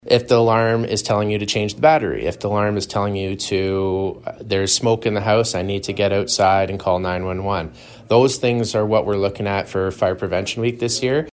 Shoreline Classics studio yesterday morning.